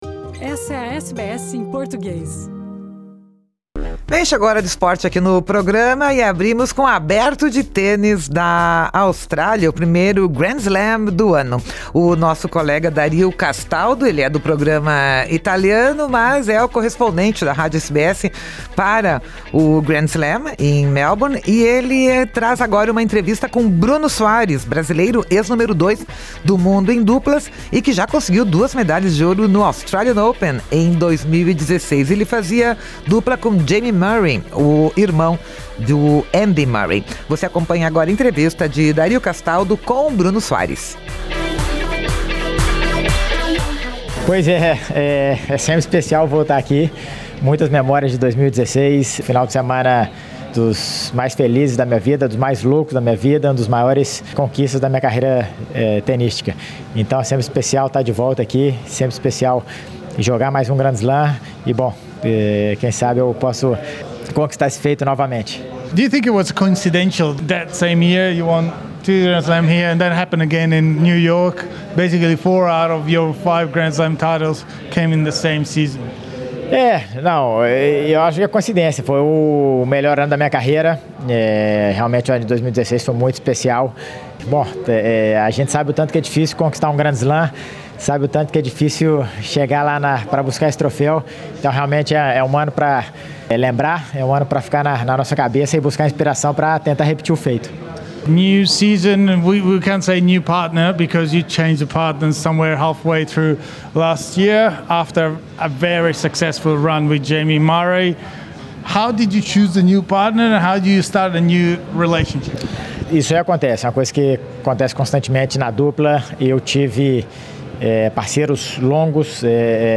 A entrevista foi concedida antes do jogo contra os australianos.